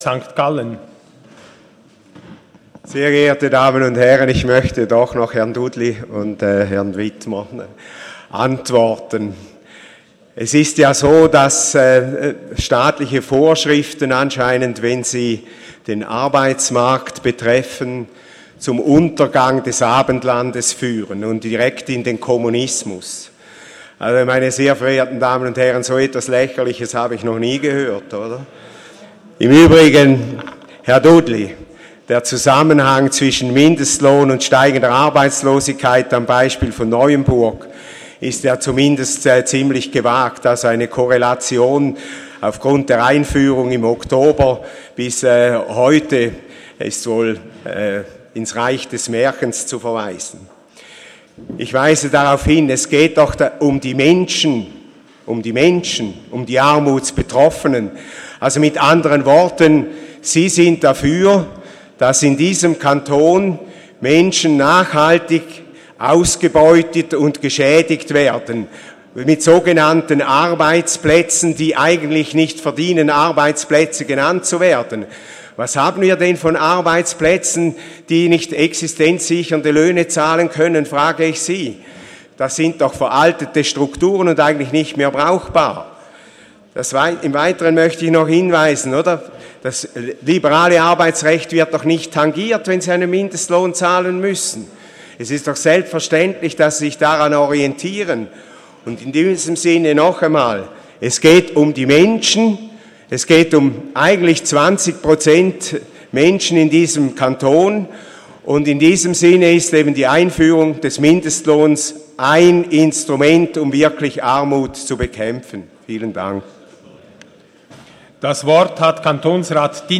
13.6.2018Wortmeldung
Session des Kantonsrates vom 11. bis 13. Juni 2018